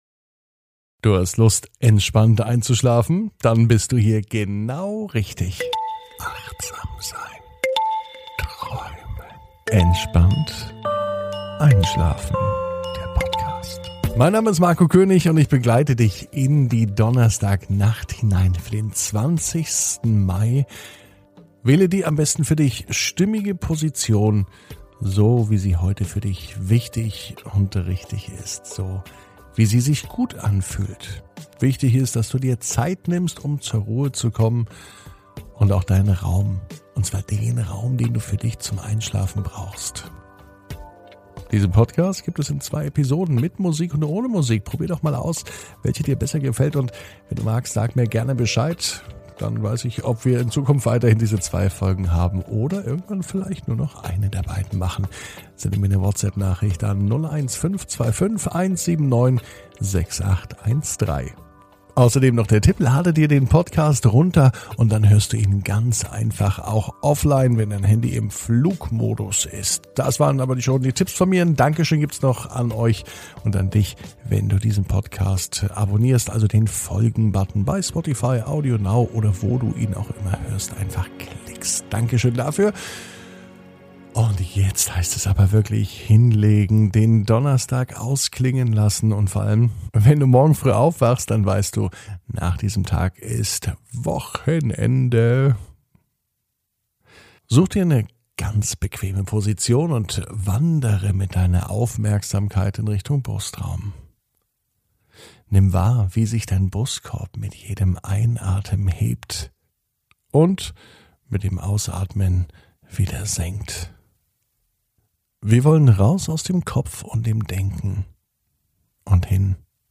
(Ohne Musik) Entspannt einschlafen am Donnerstag, 20.05.21 ~ Entspannt einschlafen - Meditation & Achtsamkeit für die Nacht Podcast